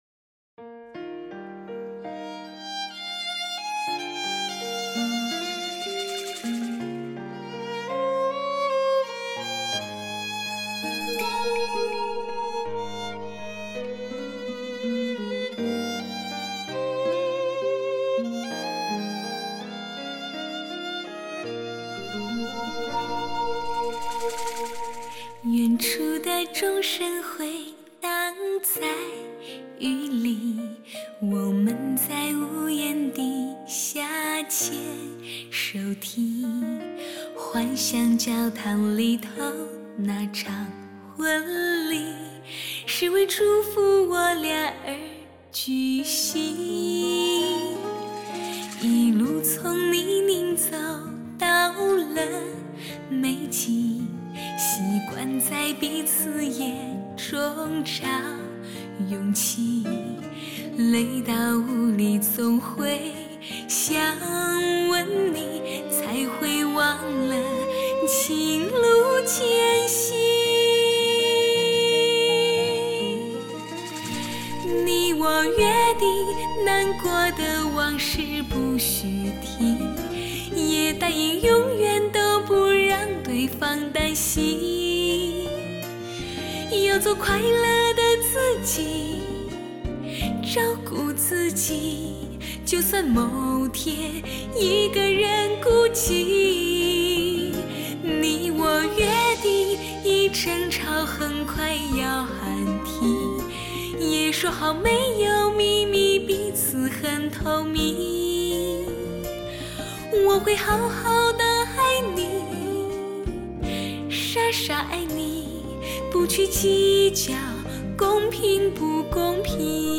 清纯和柔美的声音，演绎经典流行的完美组合，
无与伦比的磁场音效，震撼发烧天碟的无限魅力，这是声音的一次革命.
清纯柔美的嗓音将经典与流行完美的结合